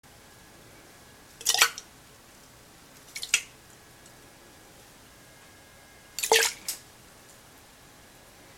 けしごむで消す 紙もぐしゃっとなったり